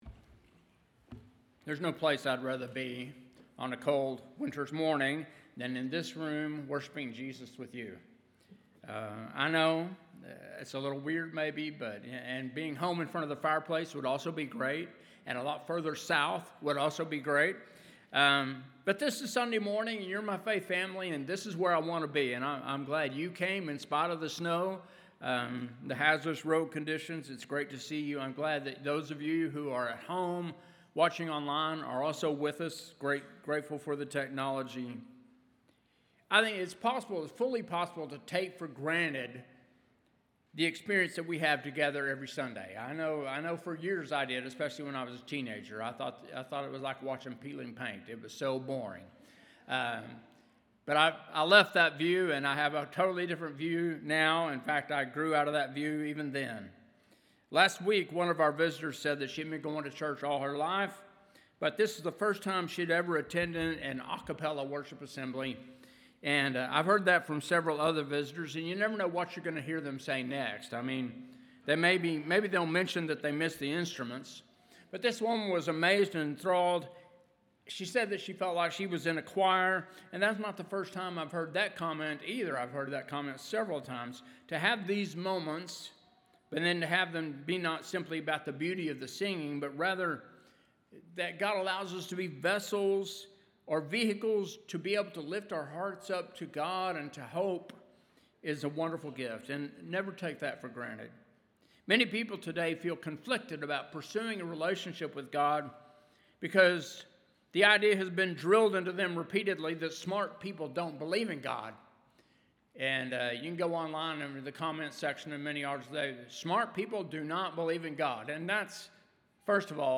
Sermons | Westport Road Church of Christ